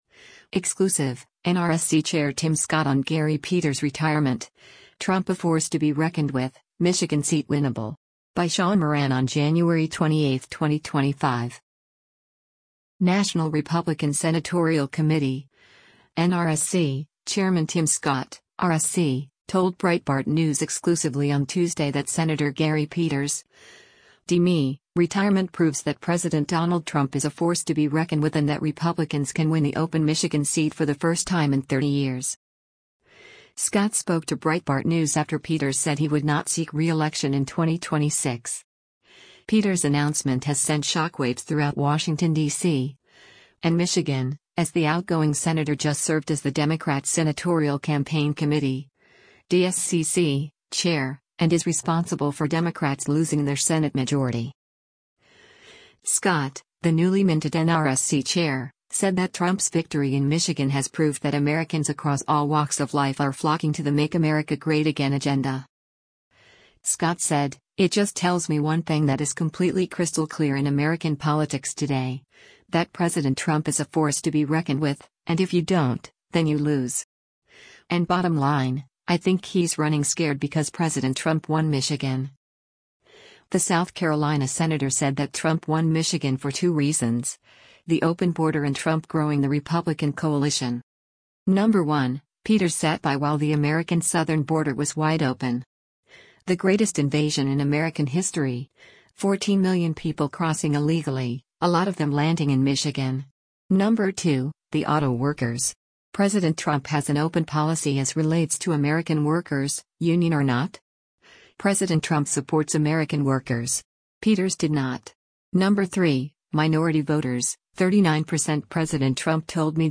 Scott spoke to Breitbart News after Peters said he would not seek reelection in 2026.